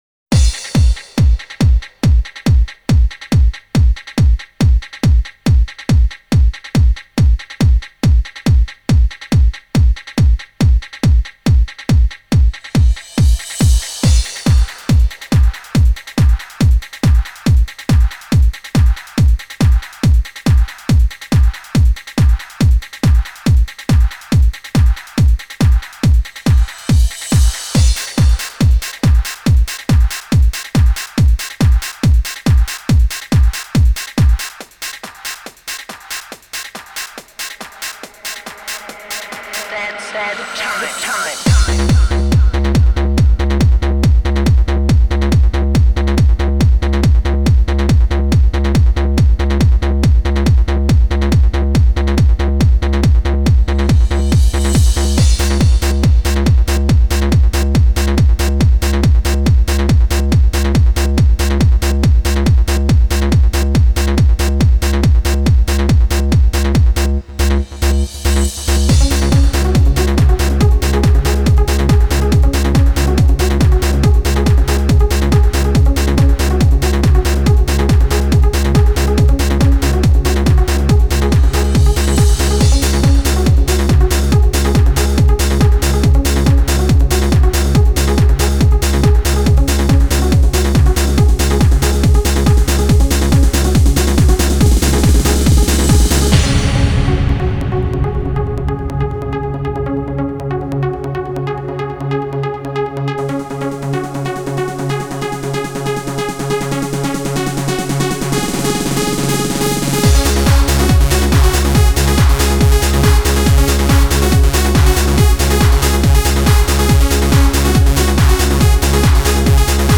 Крутой ремикс